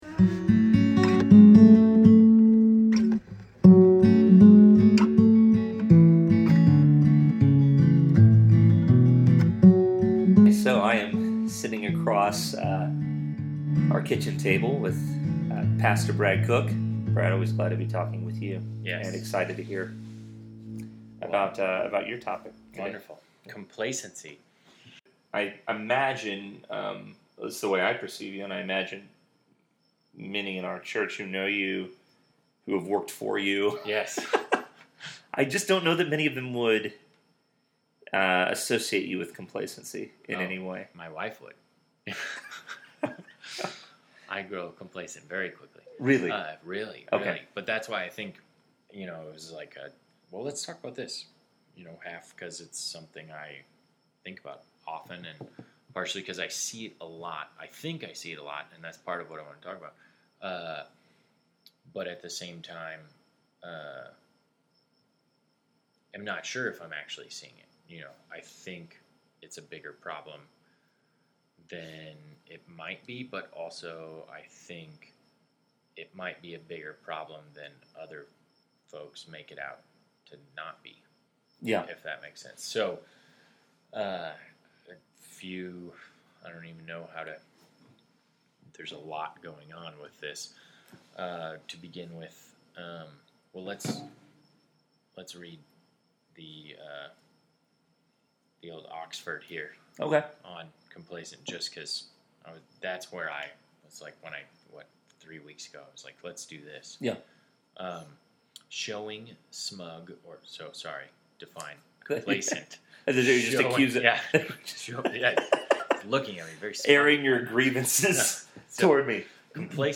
Complacency (Jive on) | A Conversation